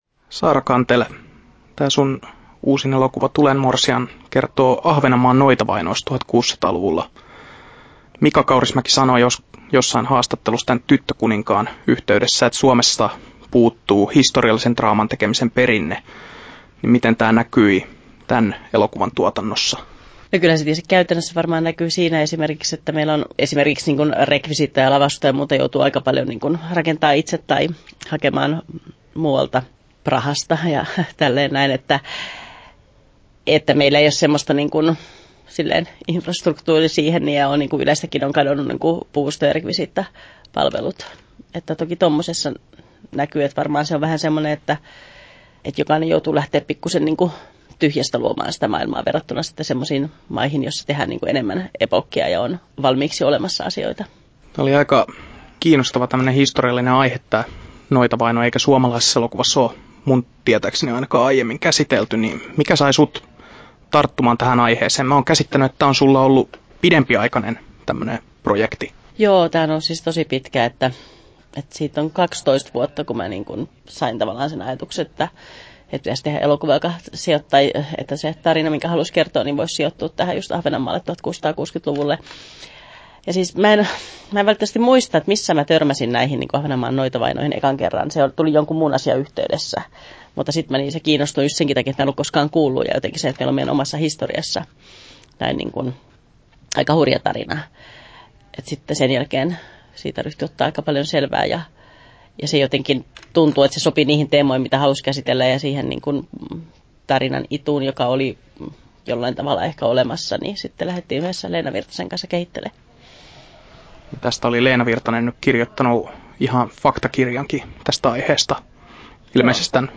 Haastattelussa
9'12" Tallennettu: 2.9.2016, Turku Toimittaja